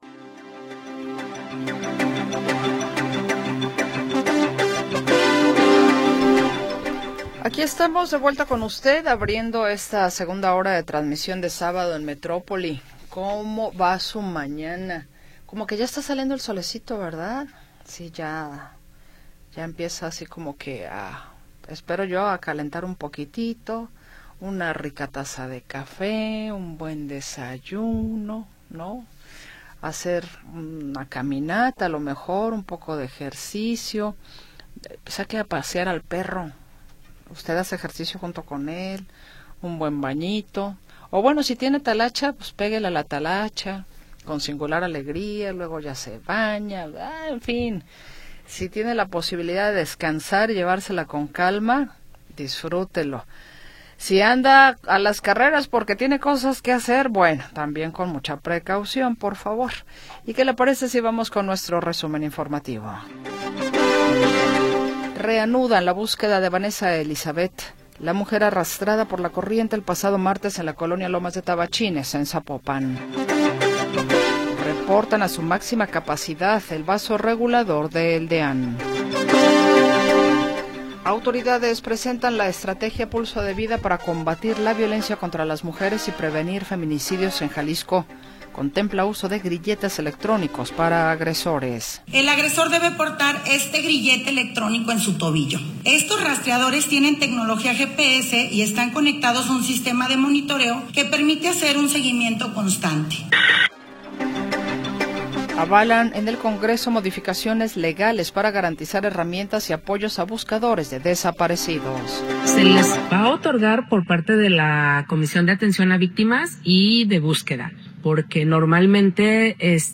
Noticias y entrevistas sobre sucesos del momento